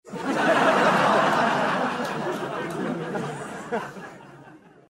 Play, download and share risas medias original sound button!!!!
risas-medias.mp3